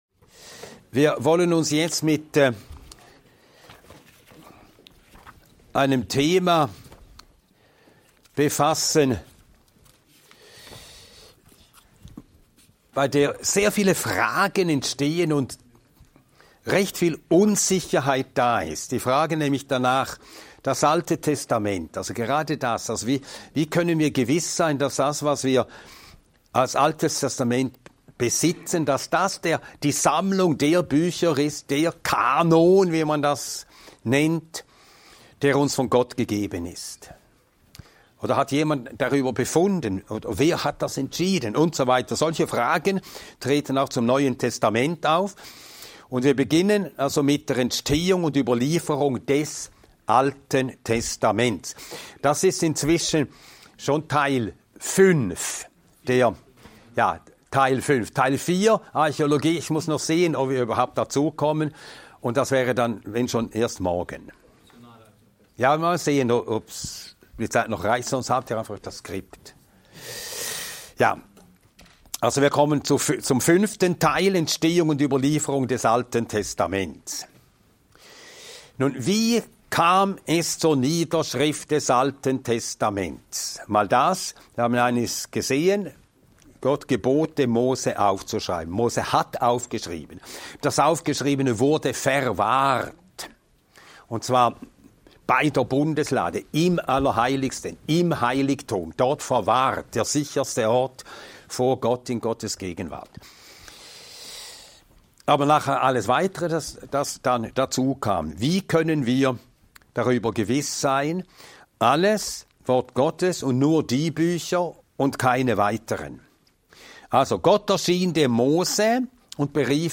Ein mehrteiliger Vortrag